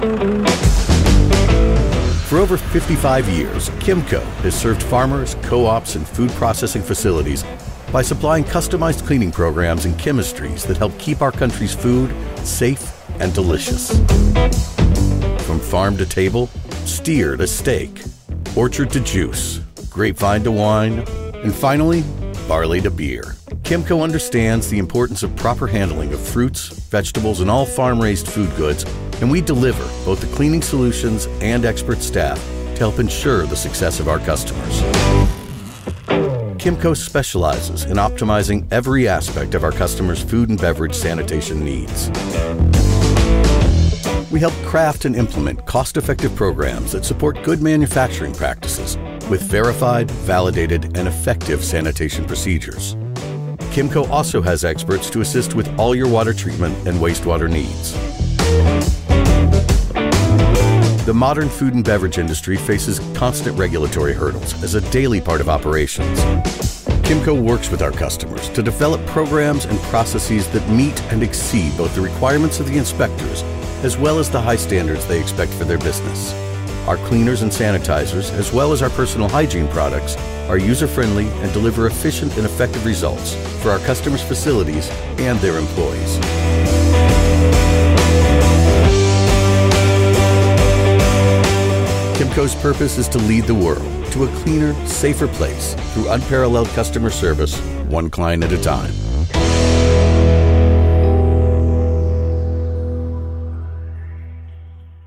Male
Yng Adult (18-29), Adult (30-50)
Corporate Narration, Chemco
0217Corporate_Food_and_Beverage_FinalProjectAudio_Chemco_CutForDemo.mp3